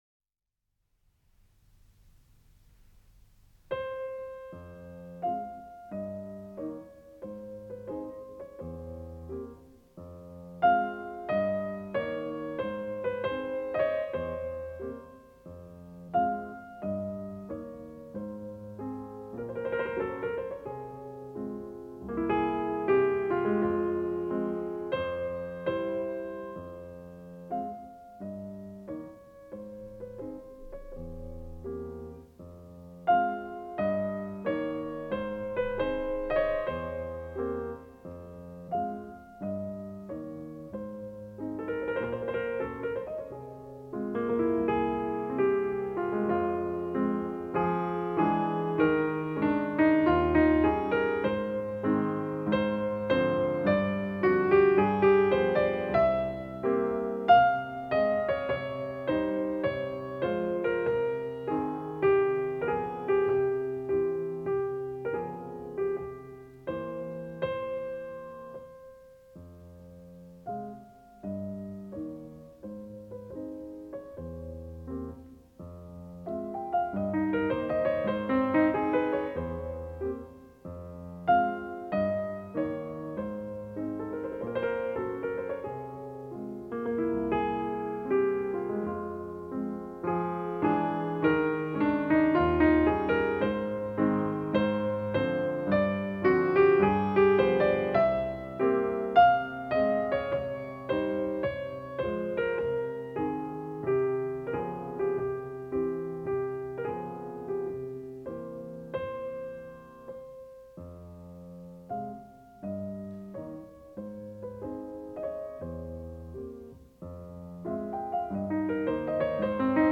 ジャズを愛する彼は音符を均等に弾きたくなかった。
本能的、直感的ともいわれ、
STEREO
アナログ的で引き締まった密度のある音と音色で、楽音も豊か。
高域は空間が広く、光彩ある音色。低域は重厚で厚みがある。